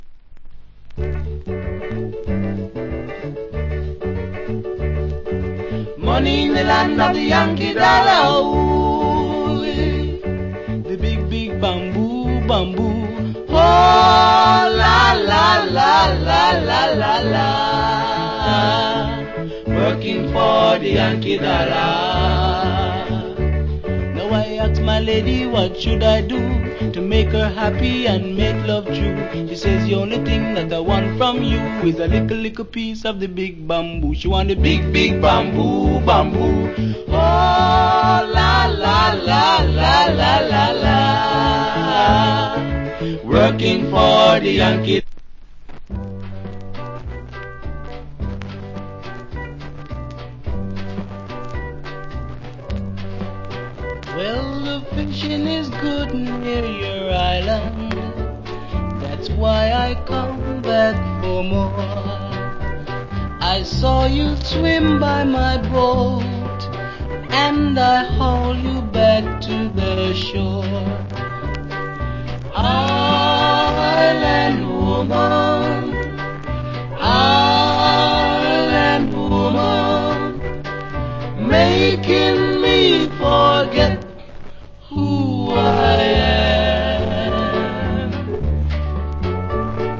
Wicked Calypso Vocal.